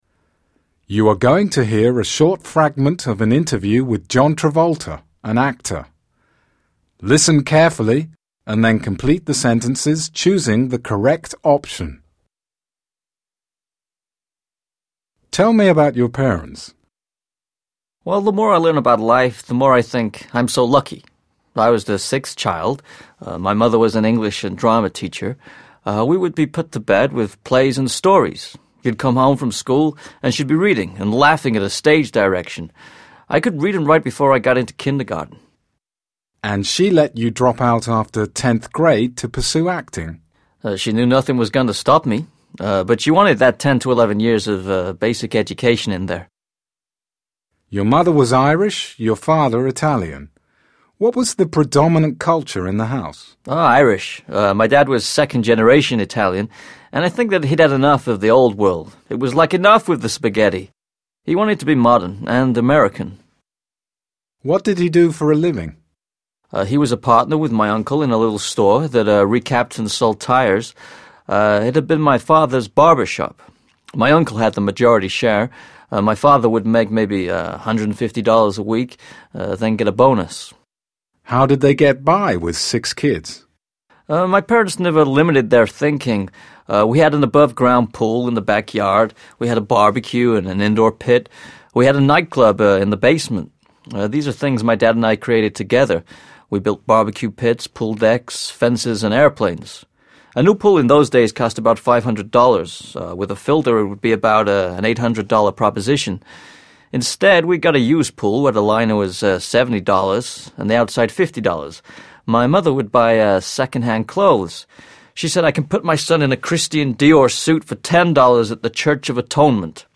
You are going to hear a short fragment of an interview with the actor John Travolta.